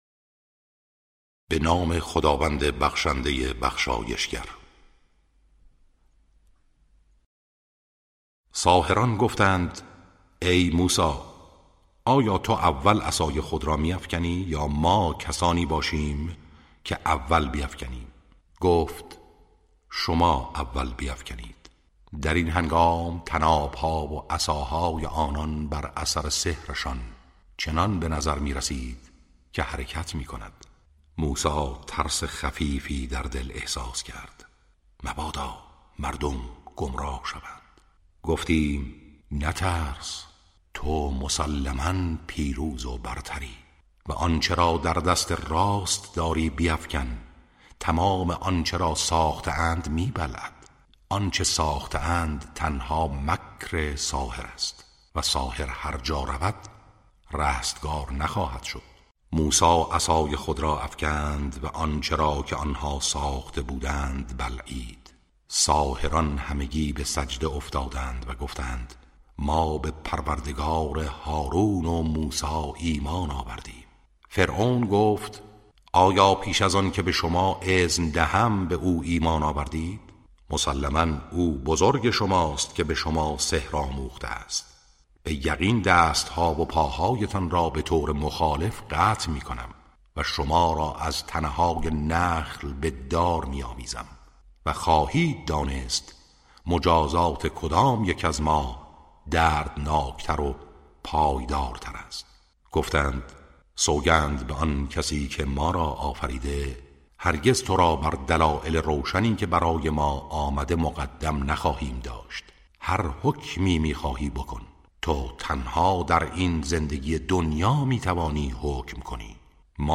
ترتیل صفحه ۳۱۶ سوره مبارکه طه(جزء شانزدهم)